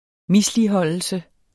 Udtale [ ˈmisliˌhʌlˀəlsə ]